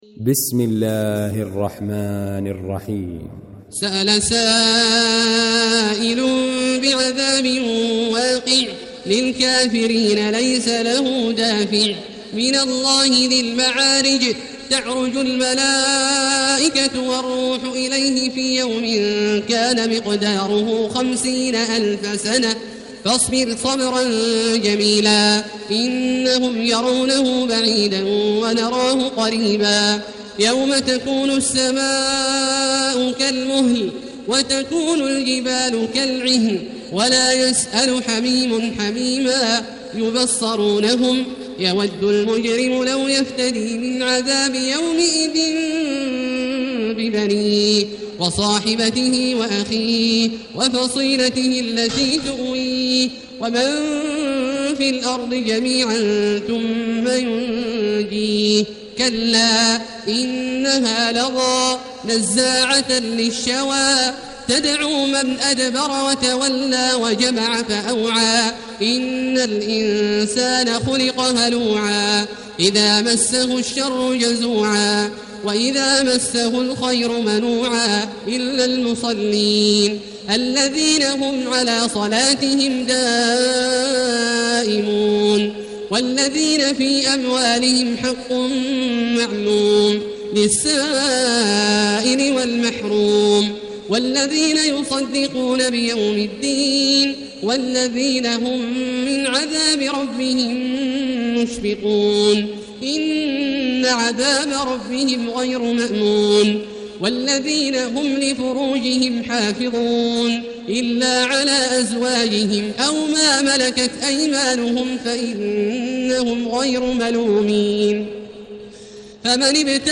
المكان: المسجد الحرام الشيخ: فضيلة الشيخ عبدالله الجهني فضيلة الشيخ عبدالله الجهني المعارج